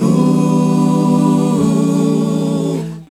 Ooo 152-A.wav